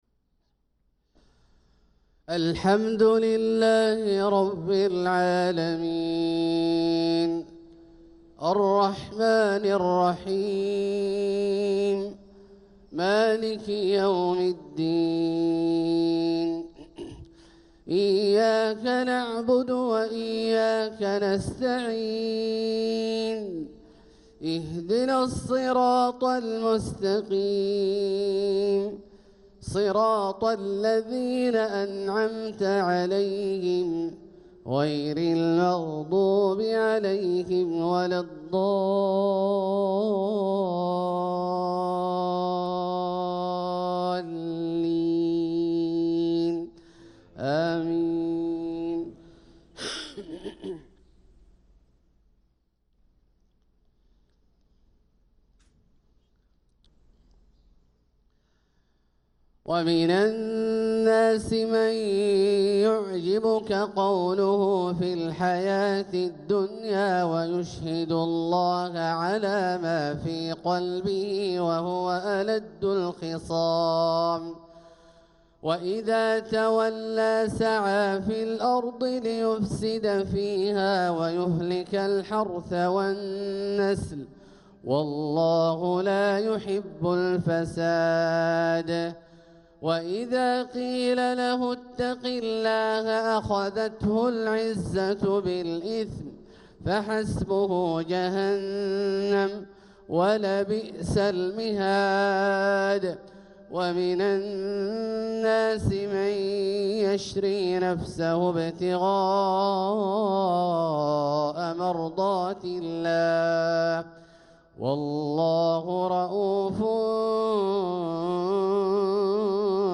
صلاة الفجر للقارئ عبدالله الجهني 22 جمادي الأول 1446 هـ
تِلَاوَات الْحَرَمَيْن .